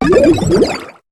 Cri de Symbios dans Pokémon HOME.